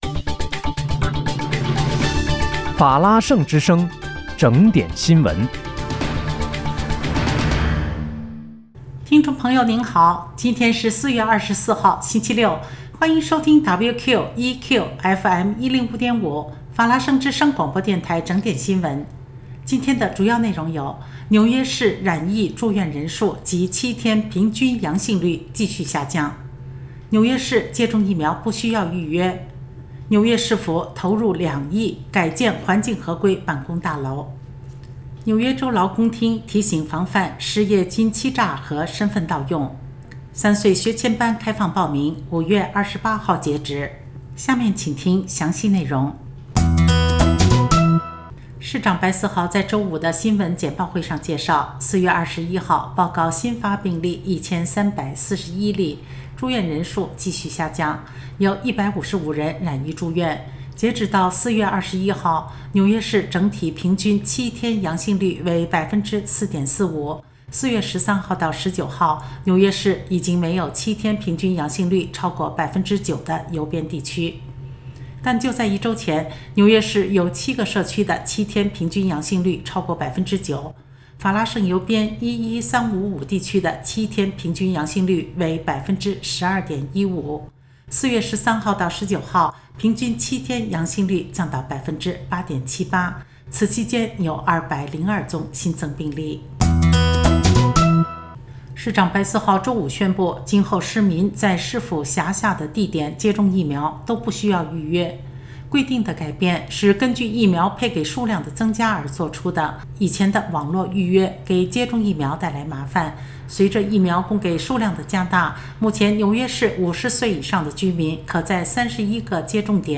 4月24日（星期六）纽约整点新闻